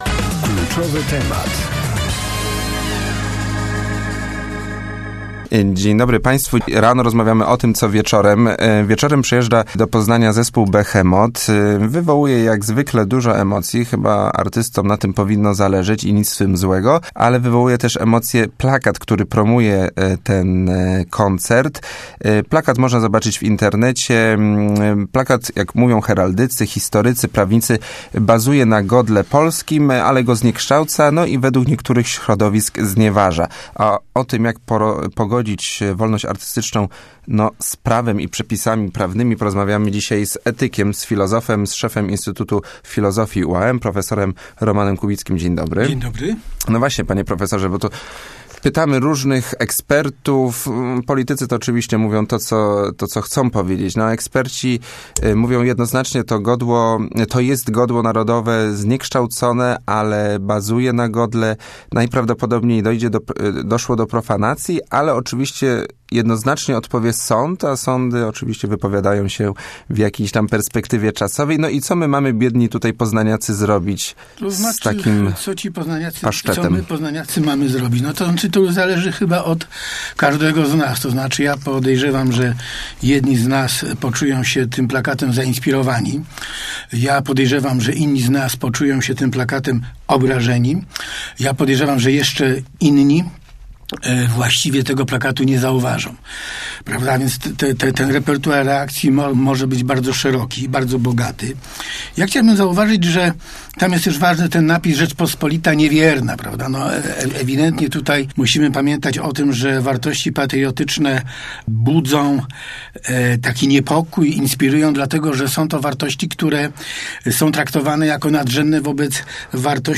(cała rozmowa poniżej)